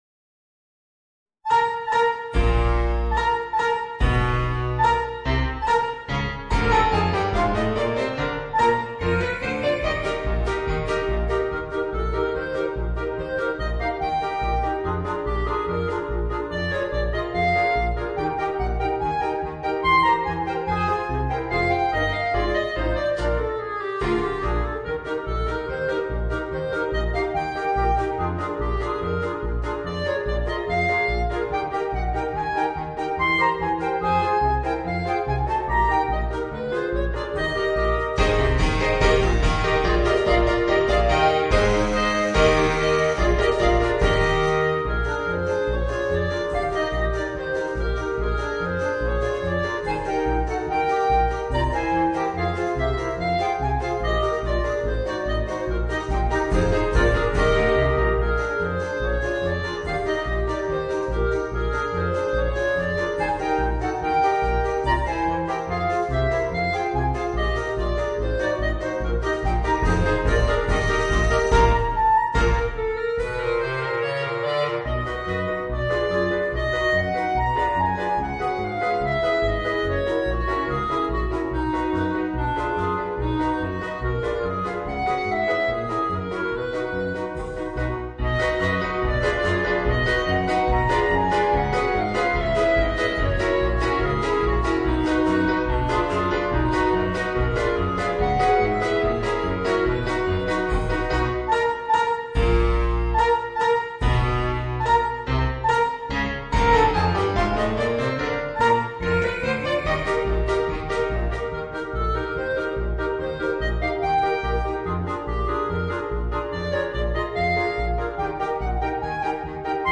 Voicing: 4 Clarinets and Rhythm Section